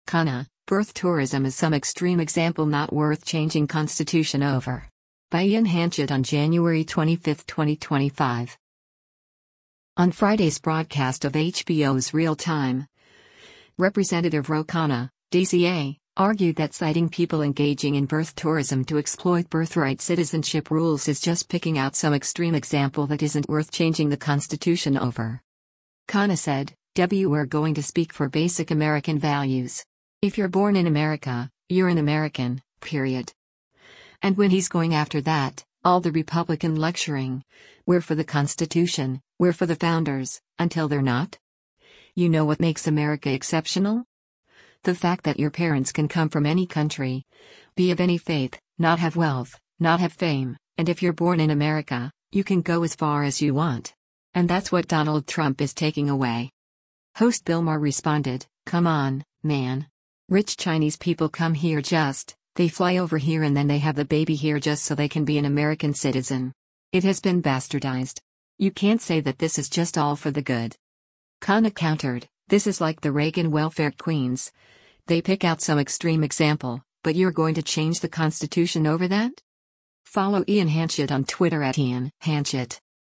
On Friday’s broadcast of HBO’s “Real Time,” Rep. Ro Khanna (D-CA) argued that citing people engaging in birth tourism to exploit birthright citizenship rules is just picking out “some extreme example” that isn’t worth changing the Constitution over.